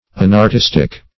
Search Result for " unartistic" : Wordnet 3.0 ADJECTIVE (1) 1. lacking aesthetic sensibility; ; [syn: inartistic , unartistic ] The Collaborative International Dictionary of English v.0.48: Unartistic \Unartistic\ See artistic . The Collaborative International Dictionary of English v.0.48: unartistic \un`ar*tis"tic\, a. Inartistic.